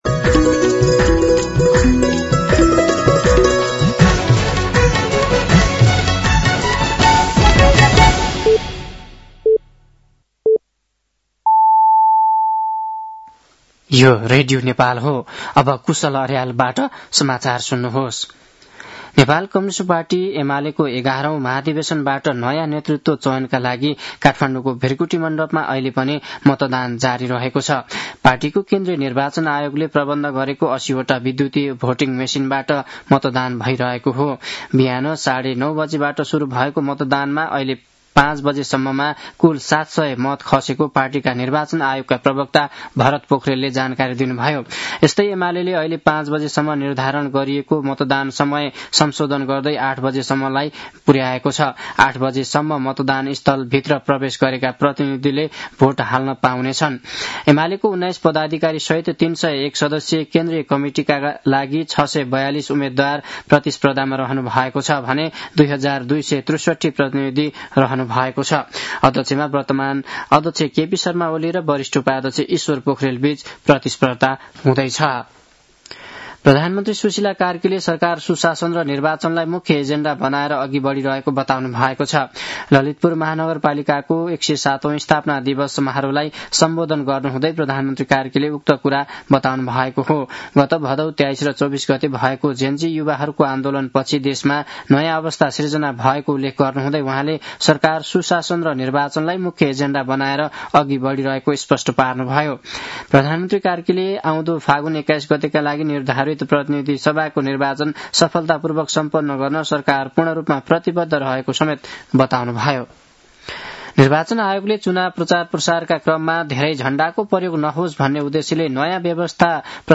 साँझ ५ बजेको नेपाली समाचार : २ पुष , २०८२
5-pm-news-9-2.mp3